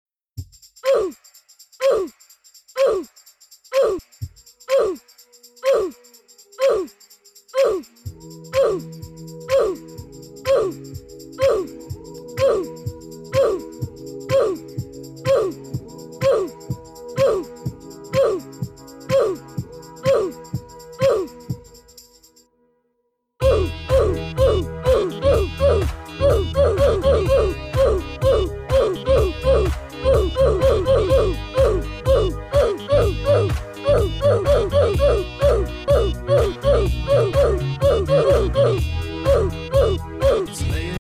Brawl Stars - character death sound. sound of defeat 41297
• Quality: High